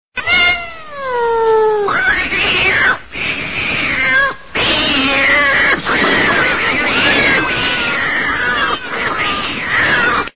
دانلود آهنگ گربه از افکت صوتی انسان و موجودات زنده
جلوه های صوتی
دانلود صدای گربه از ساعد نیوز با لینک مستقیم و کیفیت بالا